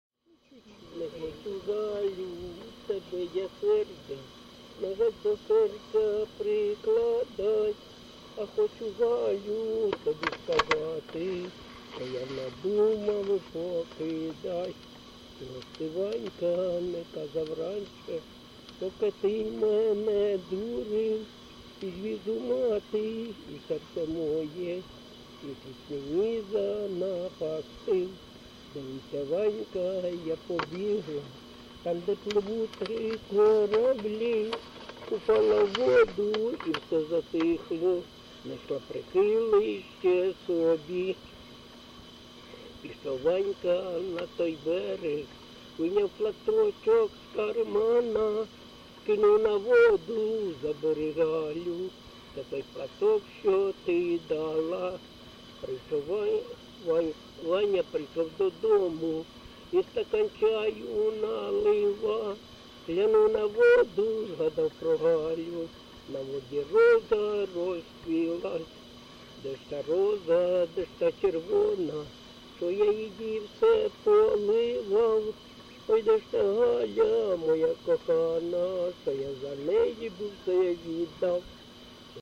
ЖанрПісні з особистого та родинного життя, Романси
Місце записус. Клинове, Артемівський (Бахмутський) район, Донецька обл., Україна, Слобожанщина